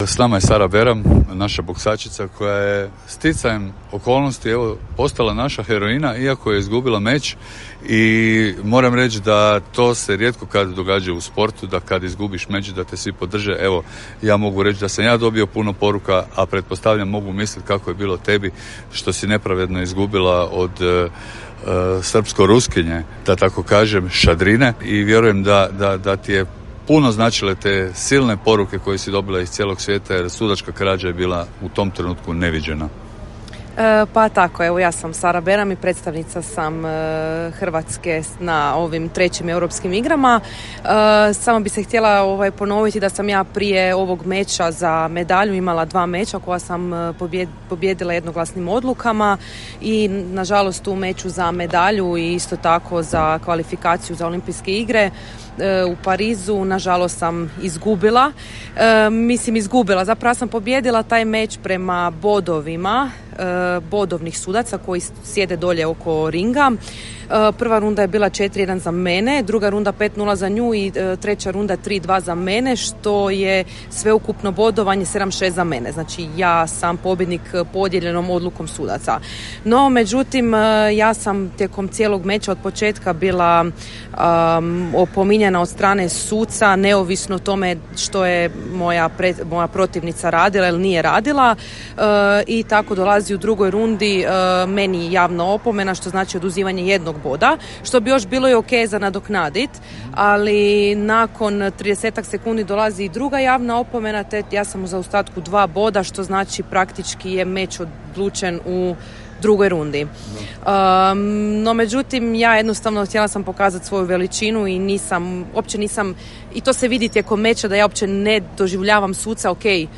KRAKOV - Europske igre u Krakovu ušle su u posljednji dan.